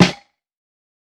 TC2 Snare 30.wav